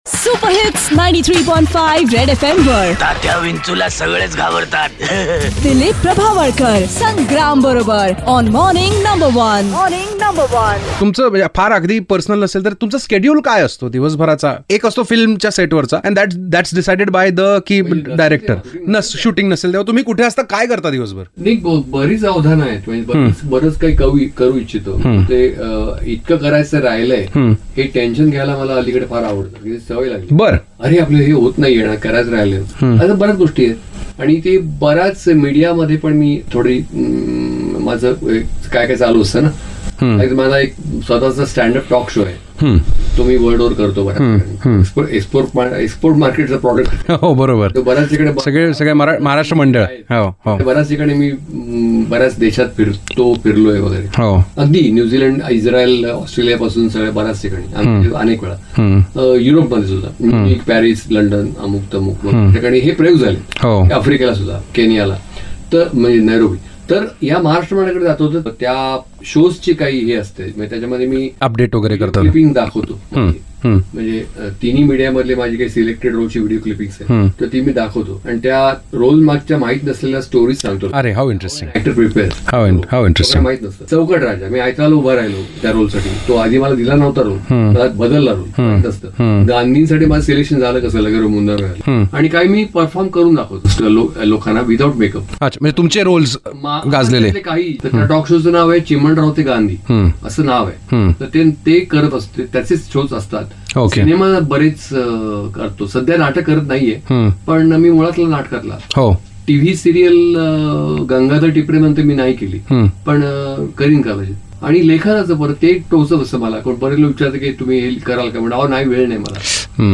Vetran Marathi Actor Dilip Prabhavalkar in a candid chat